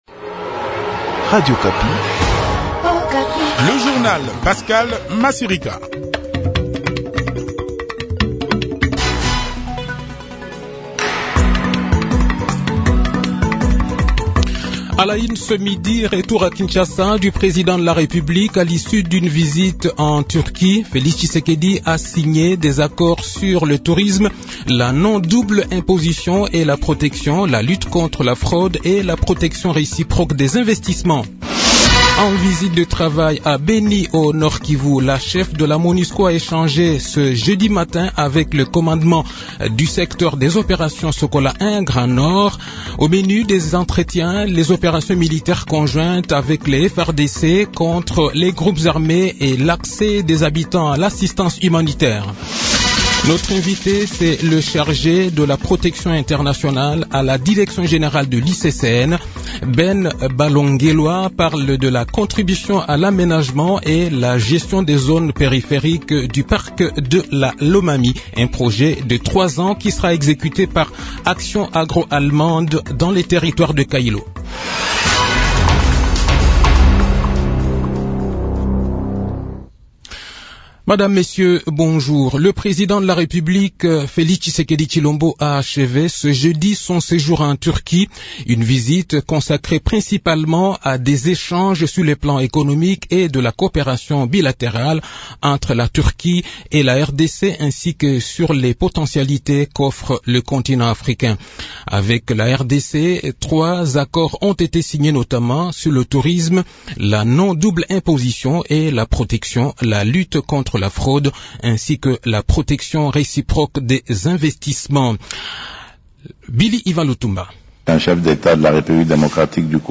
Journal midi
Le journal de 12 h, 9 Septembre 2021